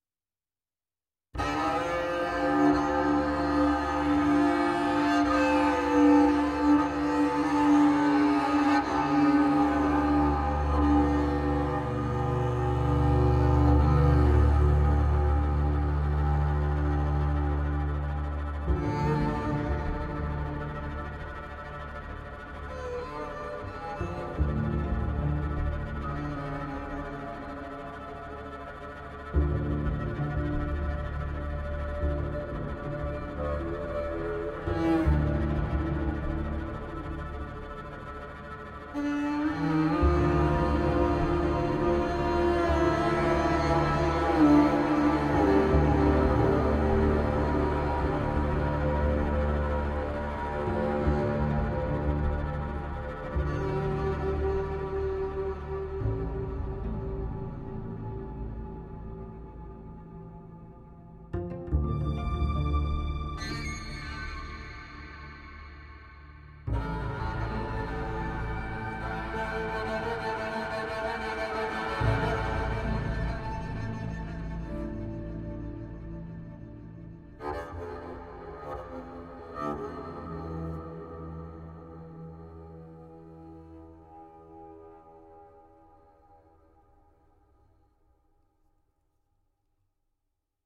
NORDISK KONTRABASS是一款独特的立式低音乐器，专为寻求新鲜和原创声音的电影作曲家和音乐制作人而设计。
Nordisk Kontrabass 由顶级创意演奏家出色地演奏，既可以作为肥厚、真实听起来的直立贝斯，也可以作为独立的作曲工具，能够提供美丽而引人入胜的弦乐和声和纹理、强大的节奏和情感旋律，让您的音乐脱颖而出。
麦克风选项：立体声麦克风DI混音麦克风
Twisted pizzicato textures
Wood body percussions
Bow textures